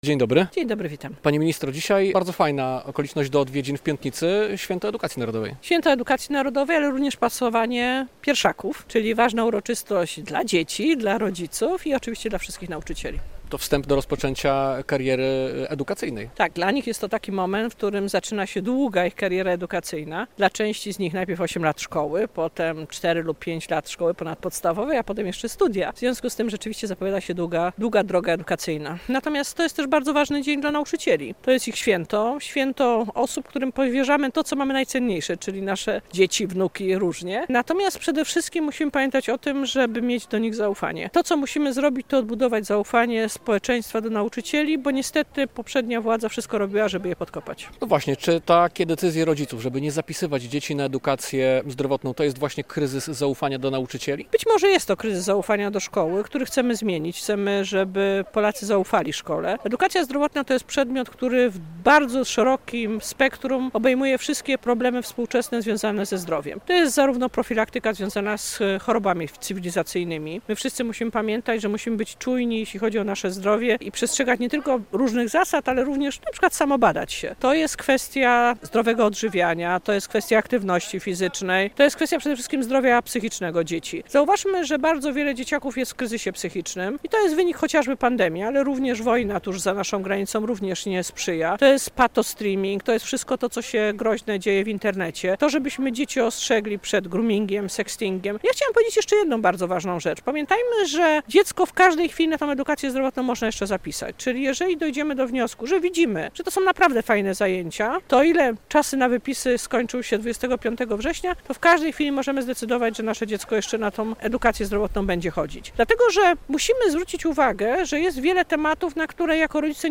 Radio Białystok | Gość | Katarzyna Lubnauer - wiceminister edukacji narodowej